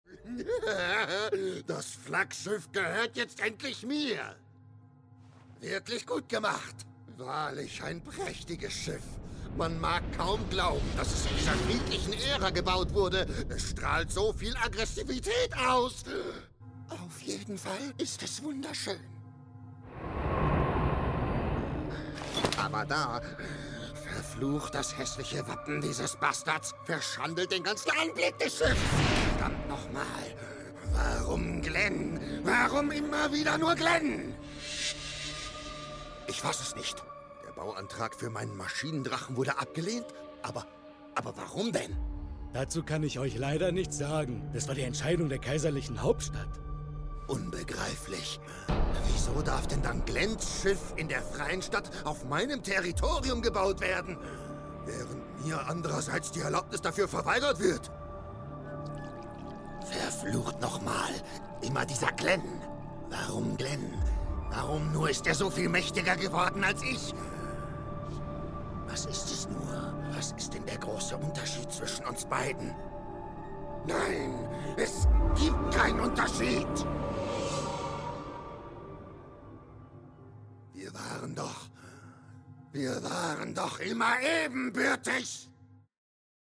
markant, dunkel, sonor, souverän, sehr variabel
Mittel minus (25-45)
Trick, Comedy